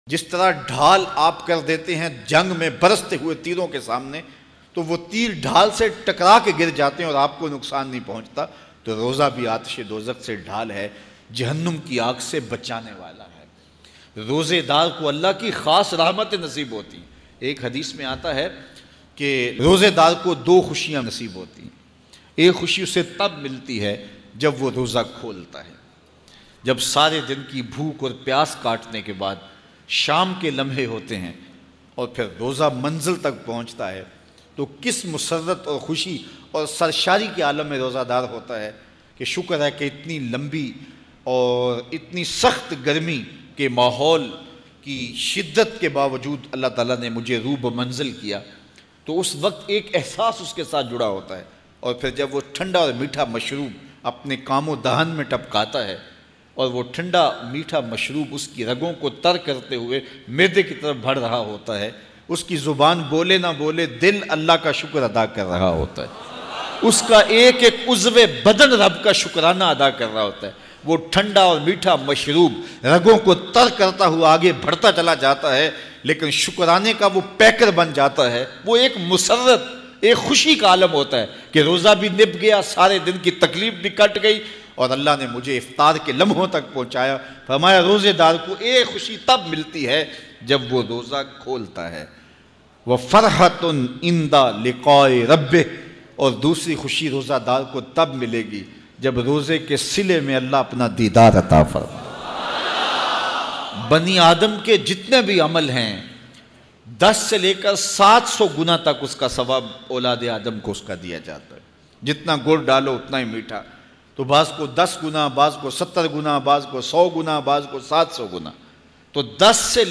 Roza Daron k liye KHUSHKHABRI New Bayan 2018 mp3.